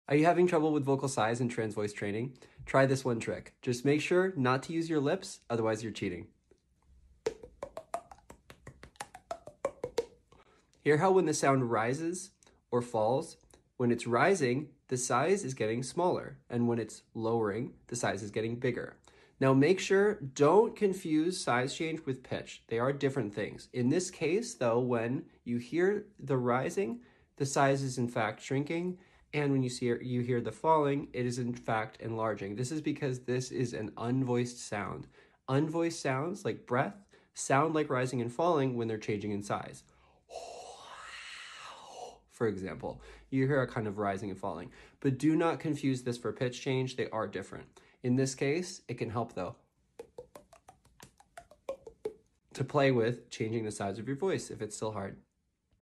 Also make sure that even though this sounds like a rising and falling, vocal size change and pitch change are different in voiced sounds!